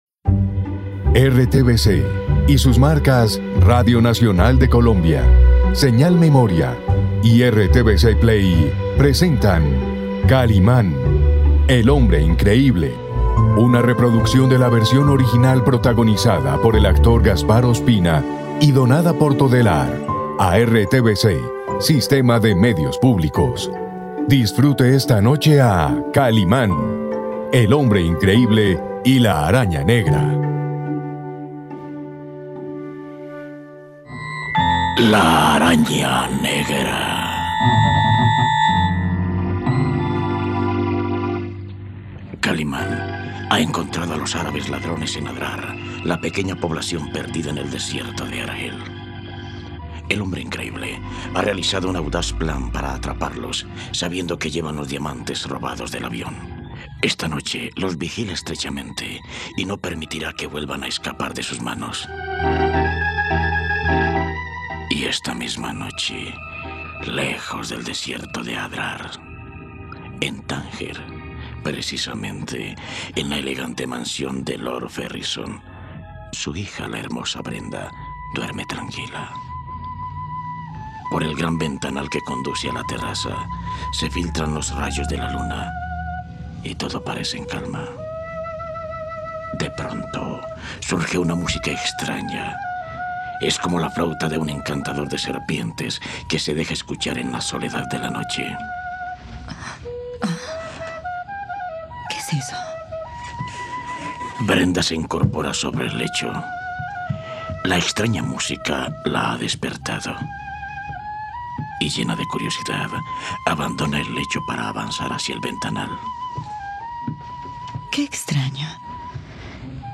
..Música de flautas de encantadores de serpientes despiertan a Brenda en la mansión.